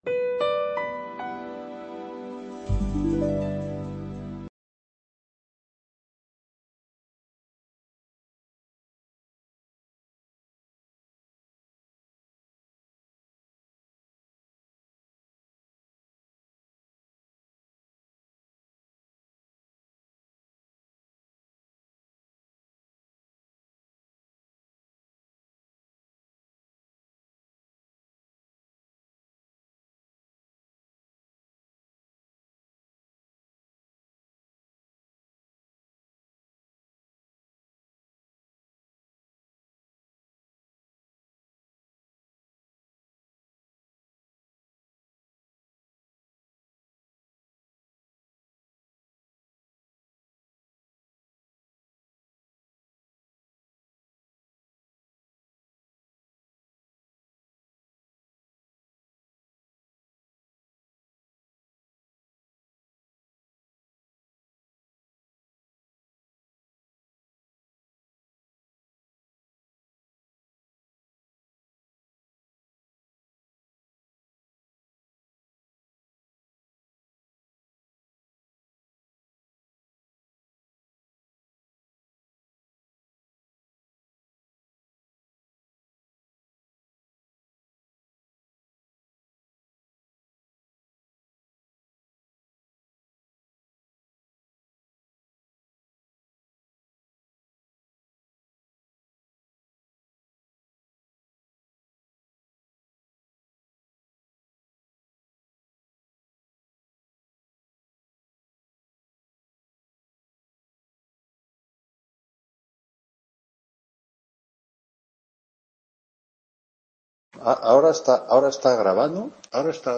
8ª TUTORÍA INTRODUCCIÓN A LA ECONOMÍA DE LA EMPRESA 13…